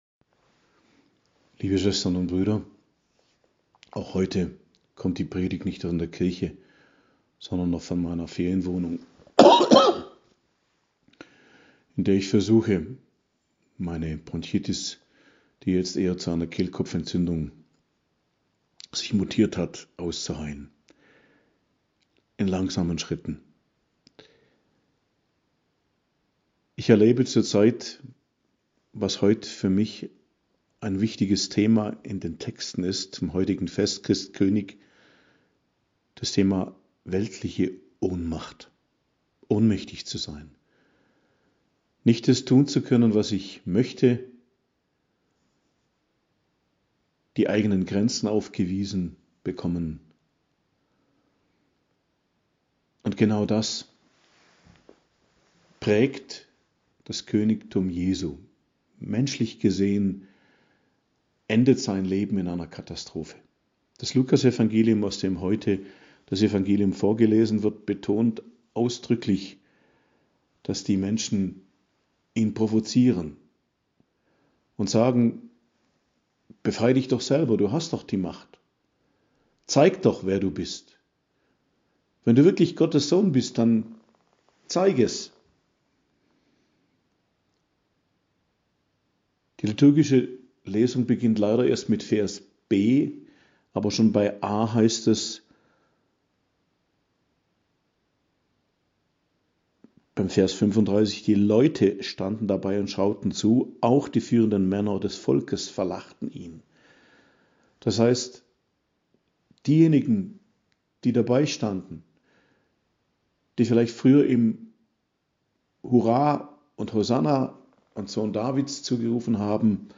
Predigt zum Christkönigssonntag, 20.11.2022 ~ Geistliches Zentrum Kloster Heiligkreuztal Podcast